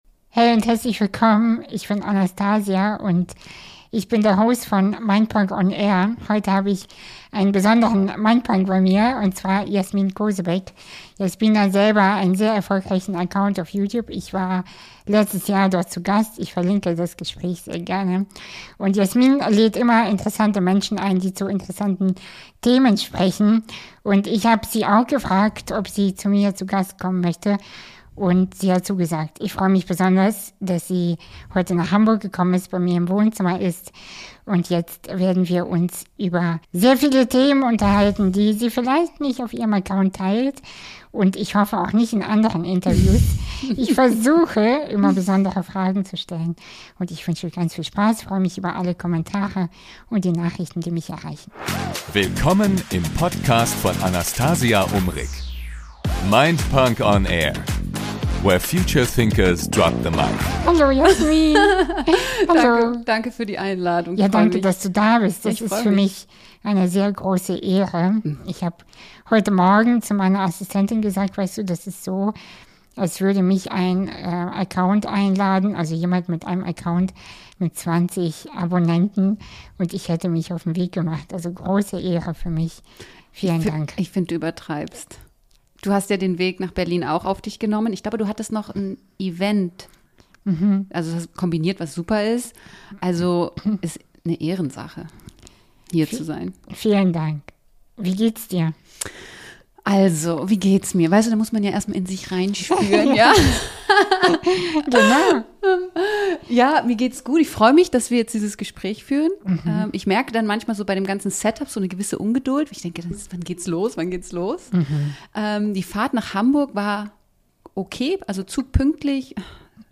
Zwei Frauen. Ein ehrliches Gespräch. Kein Skript.